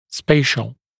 [‘speɪʃl][‘спэйшл]пространственный